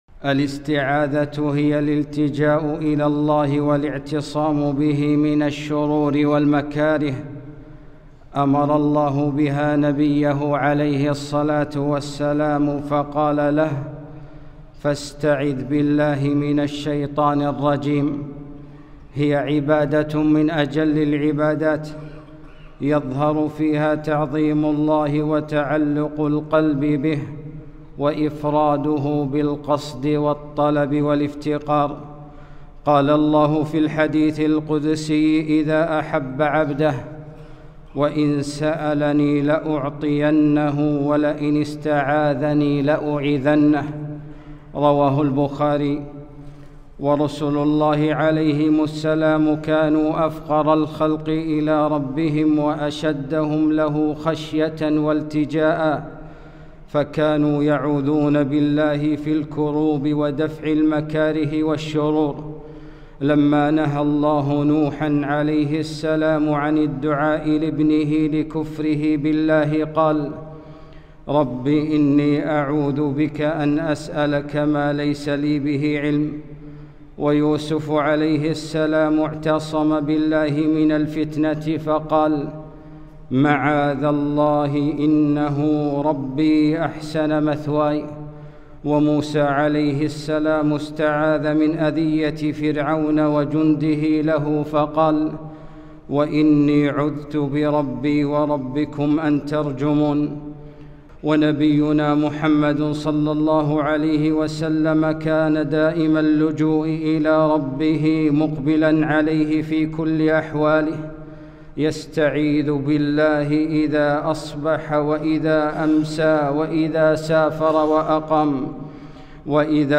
خطبة - فاستعذ بالله